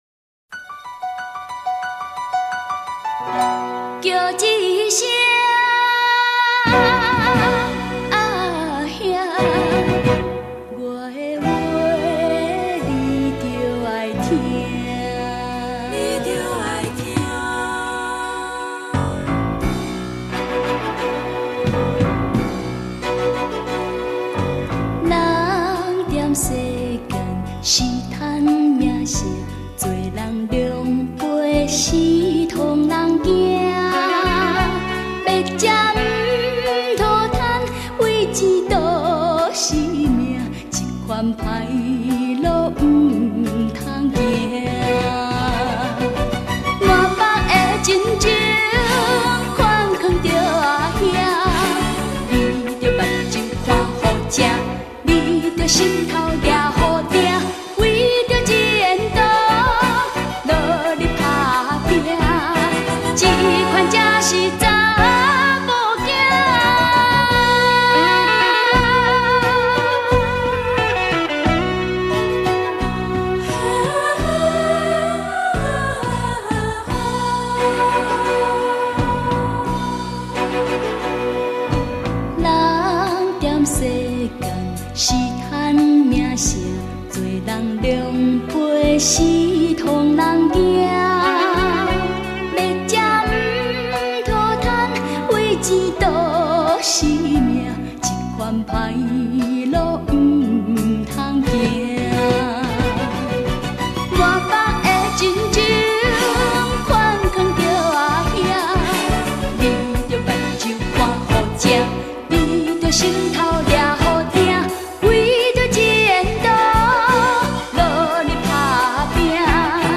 專輯語種：台語歌曲